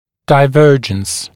[daɪ’vɜːʤəns][дай’вё:джэнс]дивергенция, расхождение, отклонение